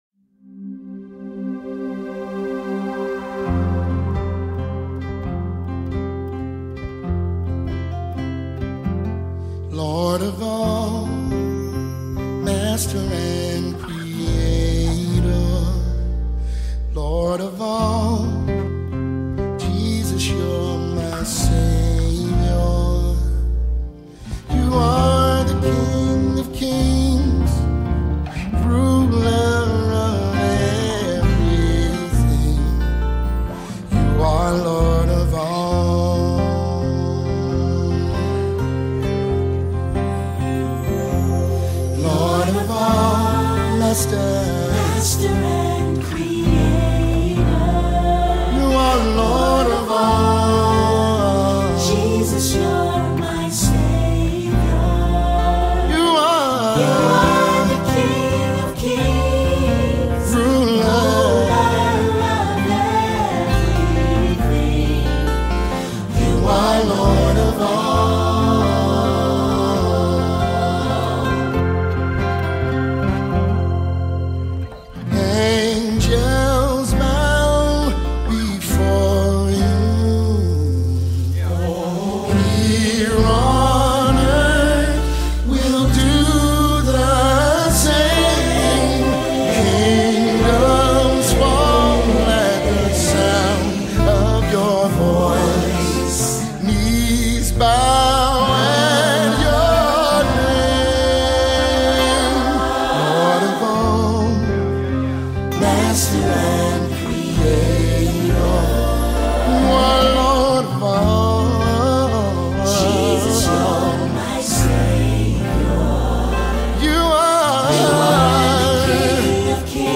Mp3 Gospel Songs
The gifted American gospel singer
offers another impressive and beautiful gospel melody